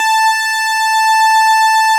Added synth instrument
snes_synth_069.wav